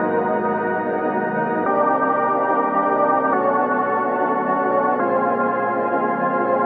K-6 Pad 2.wav